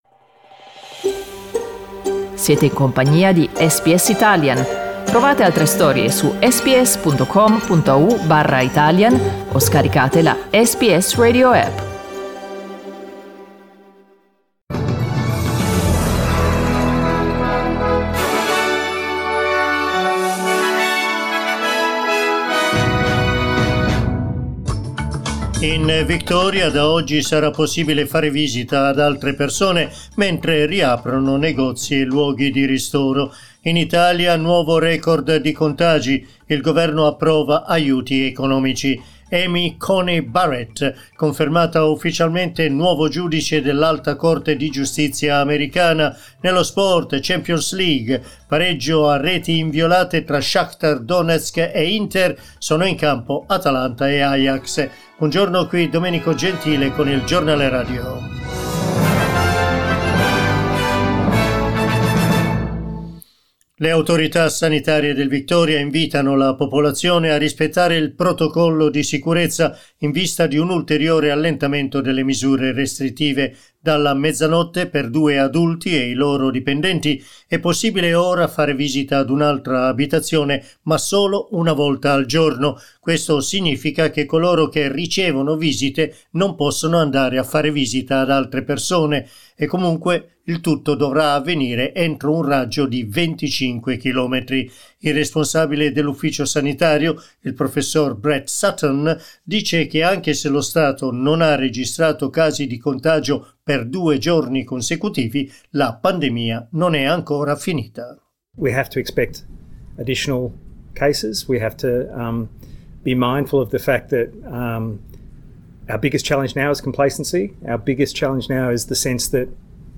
Our news bulletin in Italian.